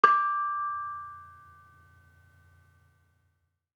Bonang-D5-f.wav